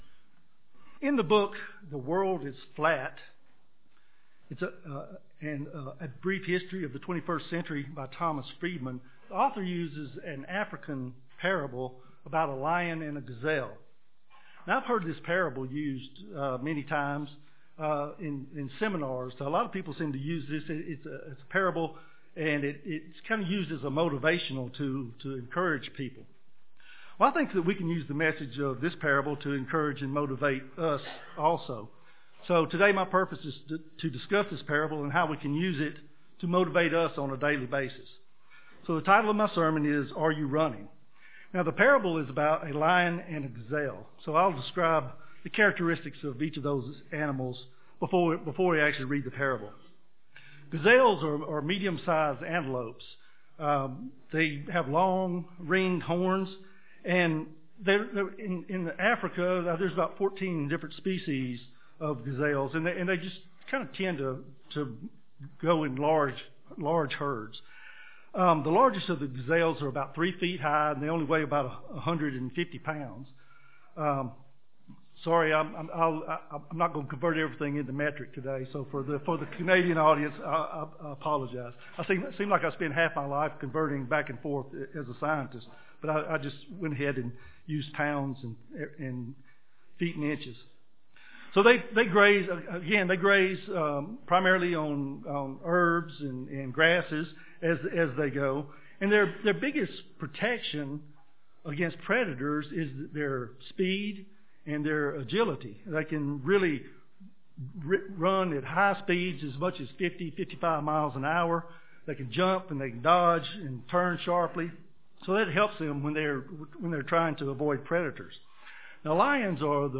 This sermon was given at the Canmore, Alberta 2016 Feast site.